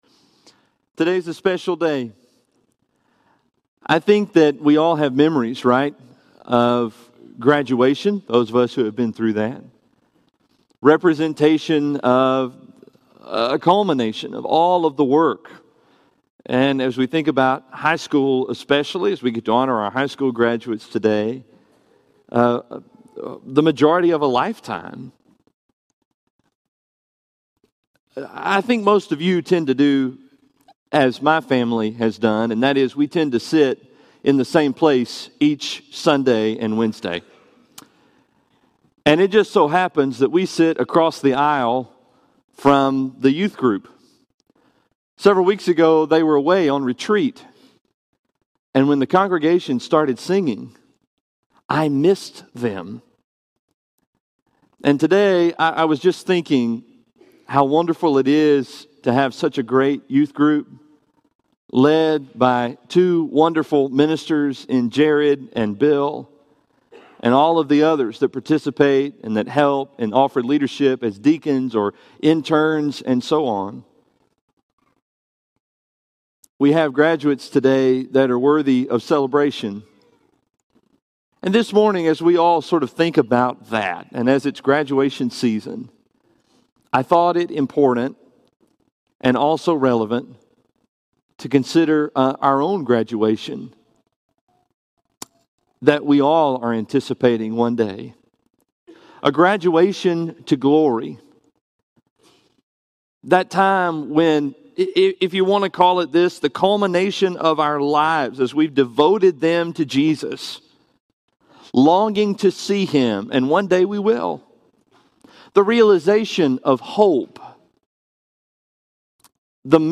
Graduation To Glory – Henderson, TN Church of Christ